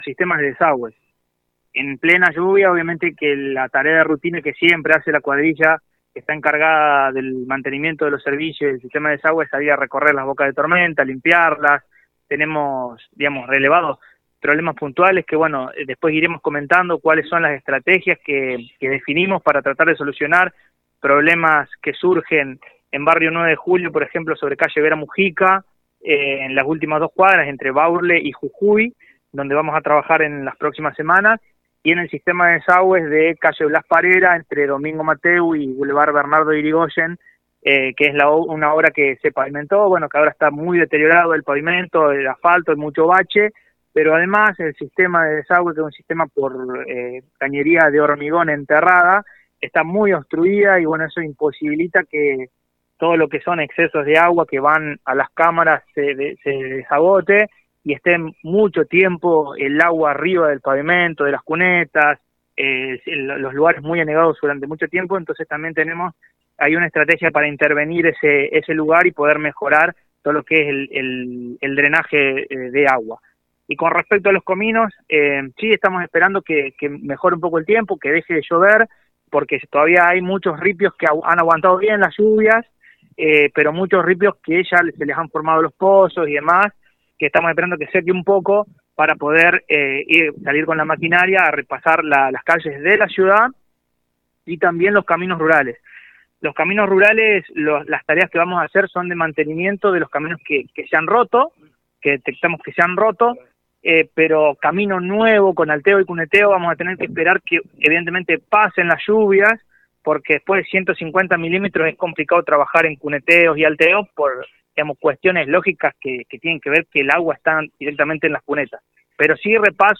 Tras las lluvias, la ciudad de Ceres necesitará que se arreglen las calles, por este motivo el Secretario de Obras Publicas dialogó con FM CERES 98.7 Mhz. para comentar sobre el trabajo que se viene en los próximos días.